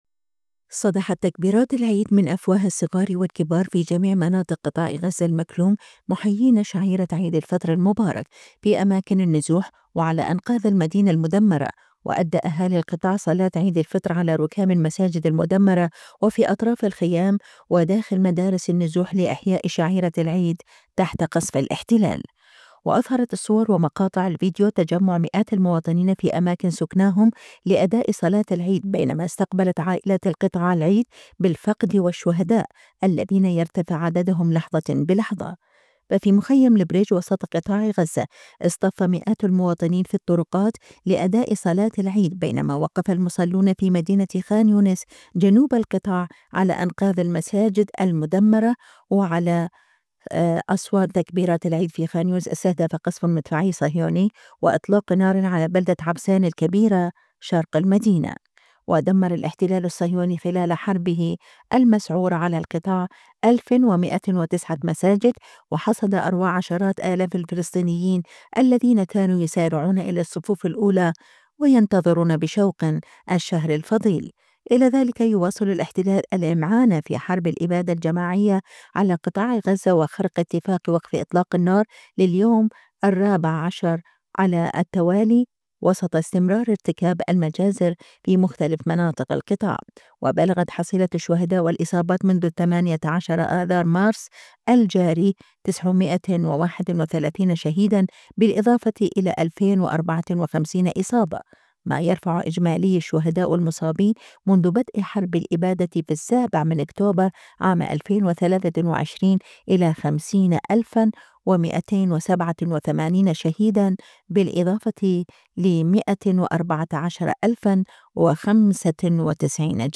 على ركام المساجد المدمرة..
أهالي قطاع غزة يؤدون صلاة عيد الفطر المبارك تحت قصف الاحتلال
صدحت تكبيرات العيد من أفواه الصغار والكبار، في جميع مناطق قطاع غزة المكلوم، مُحيين شعيرة عيد الفطر المبارك، في أماكن النزوح وعلى أنقاض المدينة المدمرة، وأدى أهالي القطاع صلاة عيد الفطر على ركام المساجد المدمرة وفي أطراف الخيام وداخل مدارس النزوح، لإحياء شعيرة العيد تحت قصف الاحتلال